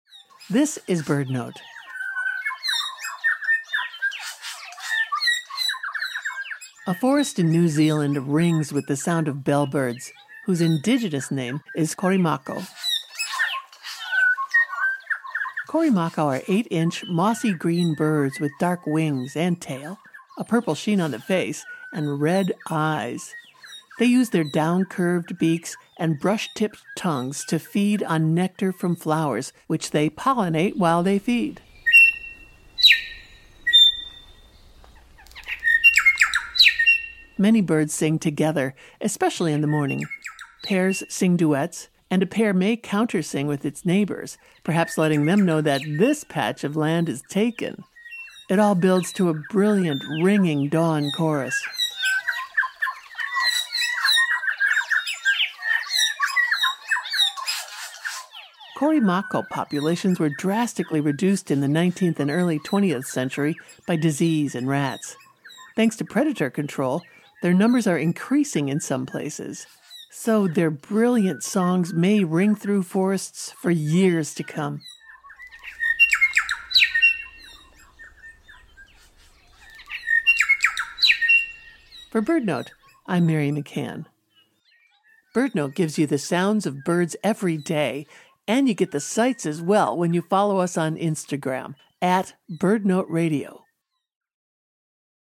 A forest in New Zealand rings with the sound of bellbirds, also known as Korimako or Makomako. Many bellbirds sing together, especially in the morning. Pairs sing duets.